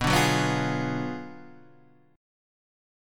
B Minor 6th Sharp 11th
Bm6+11 chord {x 2 4 1 3 1} chord